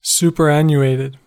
Ääntäminen
Synonyymit dated archaic outdated outmoded out of date Ääntäminen US Tuntematon aksentti: IPA : /ˌsupɚˈænjuˌeɪtɪd/ Haettu sana löytyi näillä lähdekielillä: englanti Käännöksiä ei löytynyt valitulle kohdekielelle.